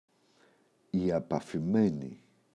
απαφημένη, η [apafi’meni]